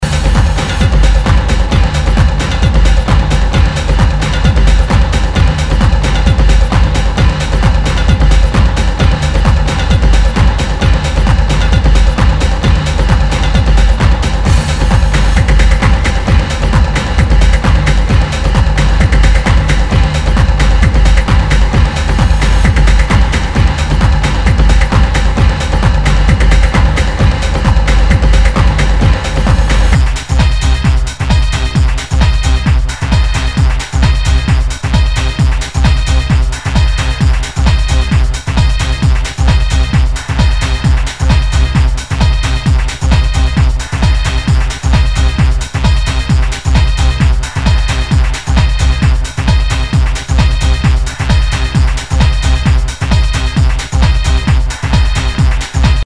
HOUSE/TECHNO/ELECTRO
ナイス！テック・ハウス！！
全体にチリノイズが入ります。